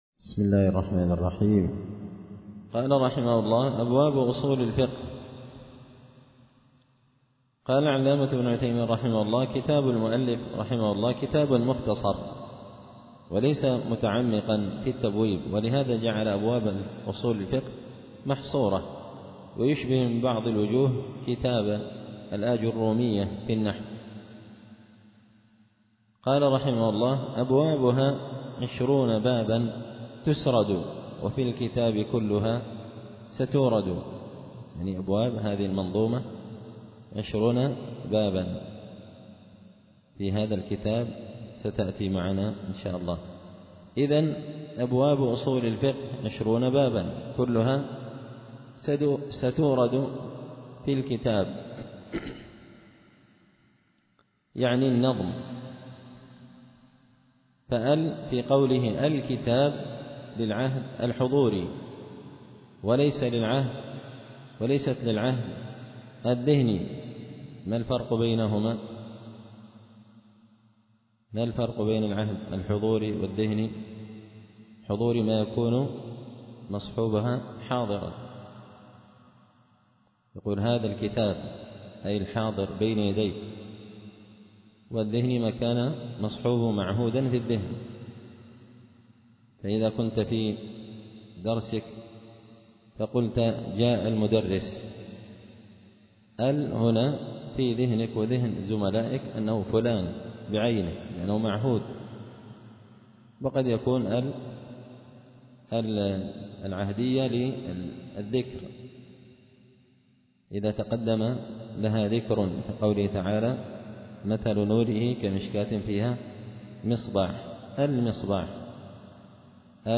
التعليقات على نظم الورقات ـ الدرس 10
دار الحديث بمسجد الفرقان ـ قشن ـ المهرة ـ اليمن